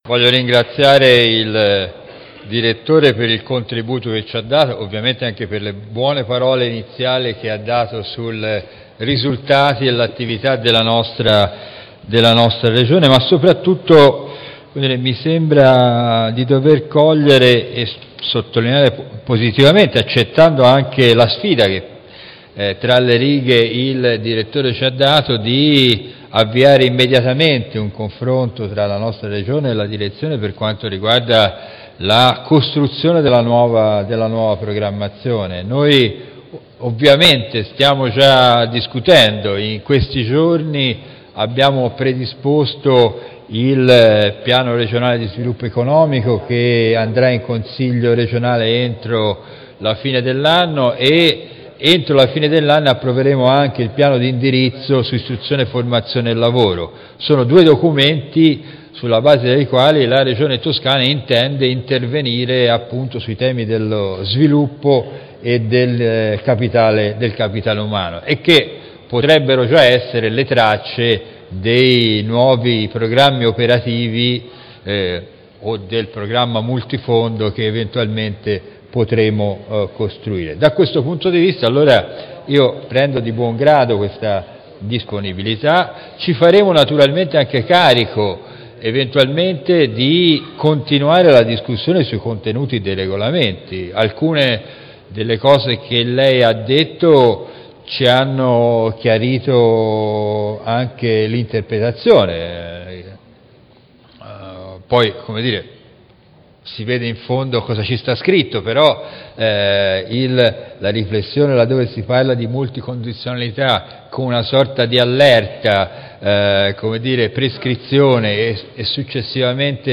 Quinto intervento della Tavola rotonda